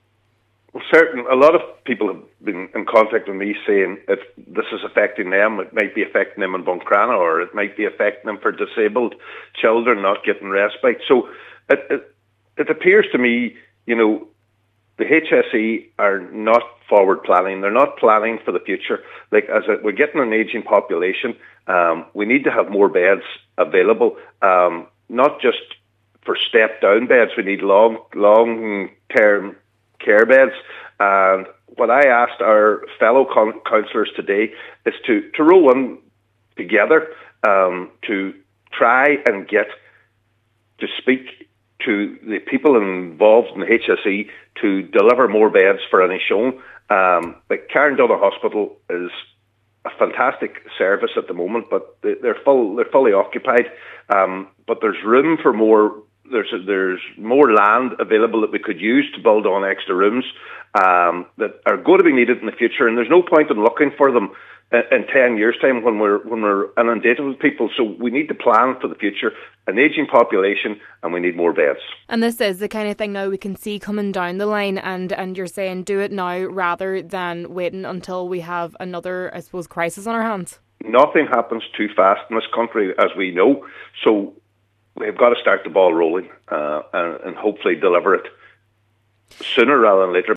He says action should happen sooner rather than later: